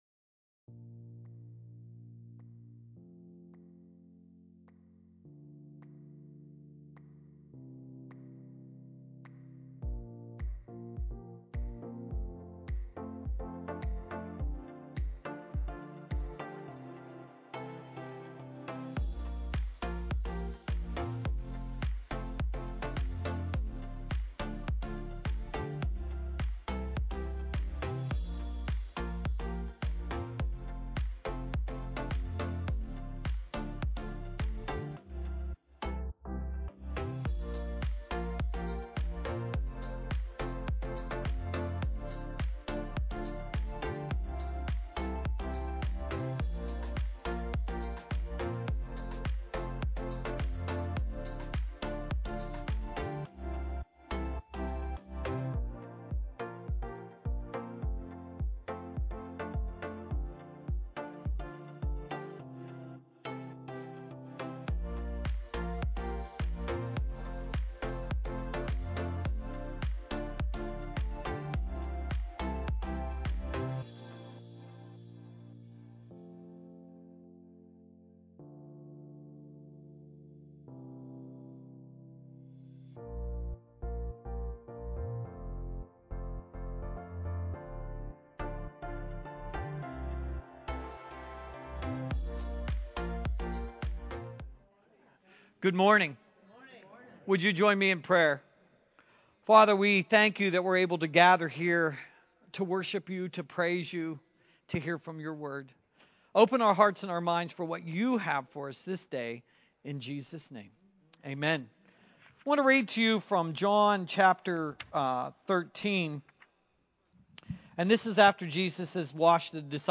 Our fantastic worship team leads us as we praise God on this beautiful Sunday morning.